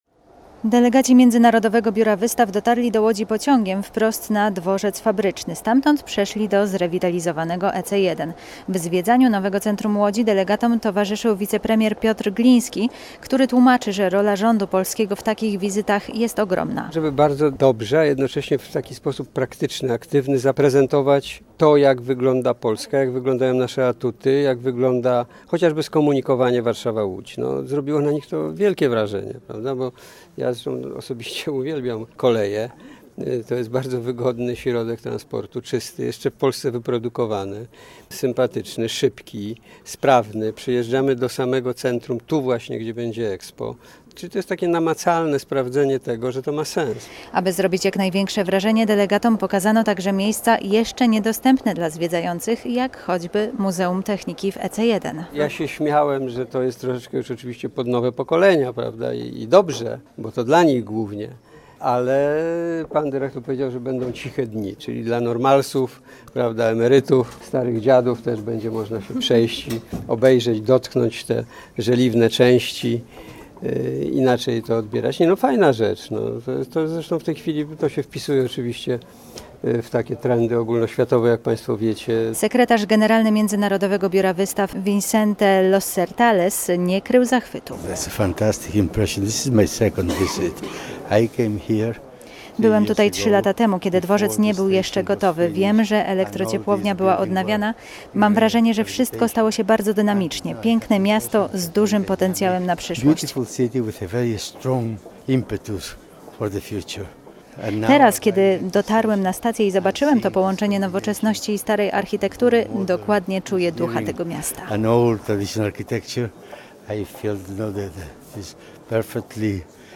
Posłuchaj relacji: Nazwa Plik Autor Delegacja BIE audio (m4a) audio (oga) Kilkudziesięciu przedsiębiorców z Łodzi i regionu podpisało deklarację poparcia dla starań miasta o organizację wystawy Expo w 2022 roku.